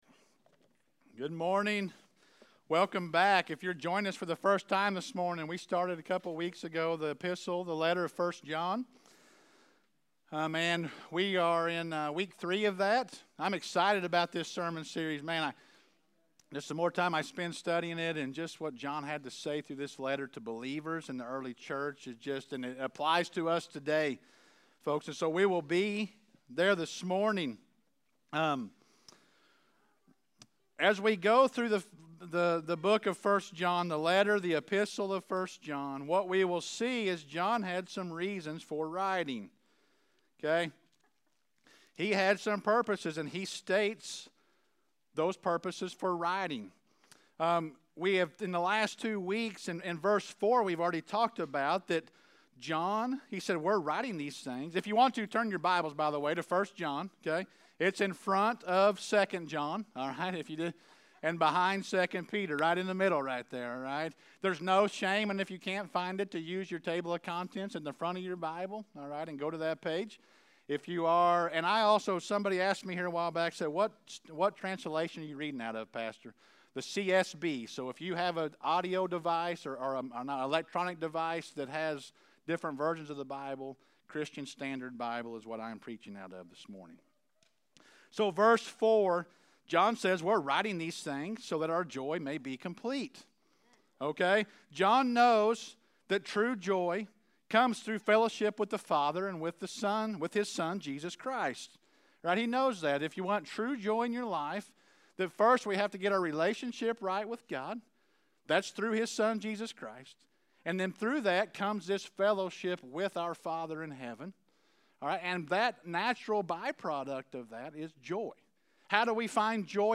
Sermon-Audio-1-24-21.mp3